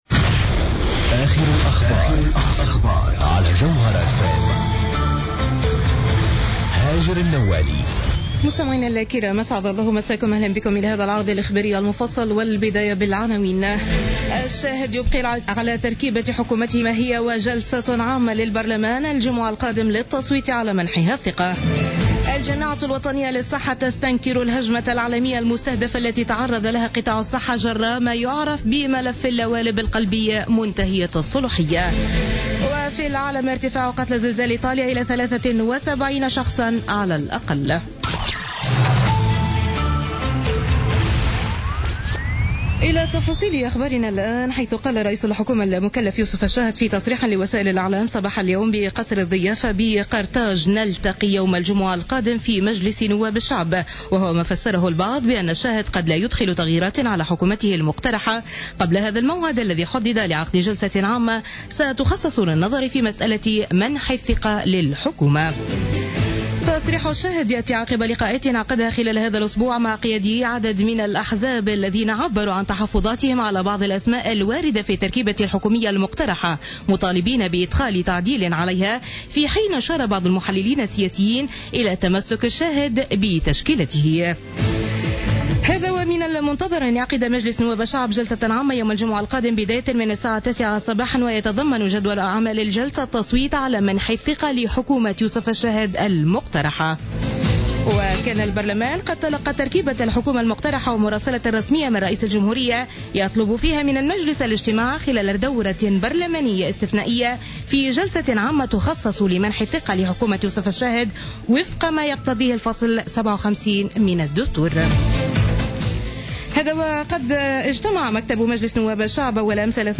نشرة أخبار السابعة مساء ليوم الأربعاء 24 أوت 2016